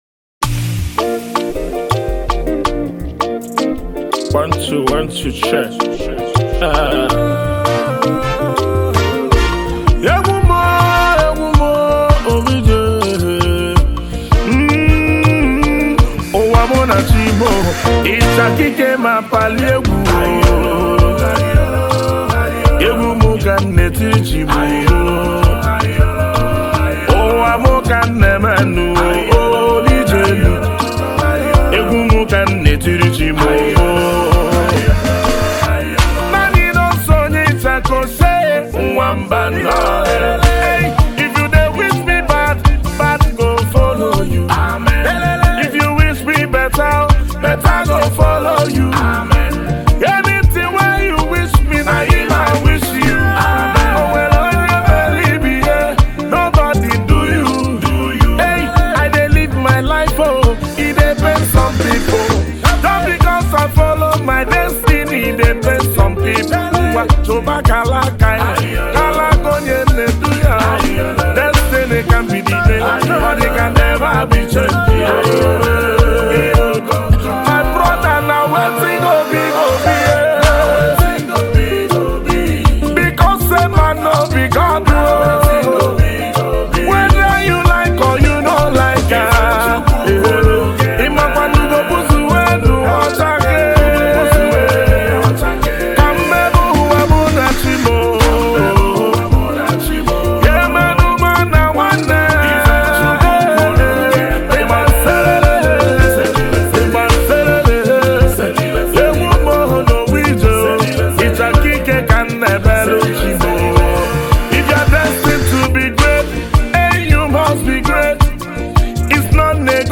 February 18, 2025 Publisher 01 Gospel 0